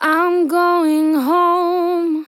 I'm going home Vocal Sample
Categories: Vocals Tags: DISCO VIBES, dry, english, female, going, home, Im, LYRICS, sample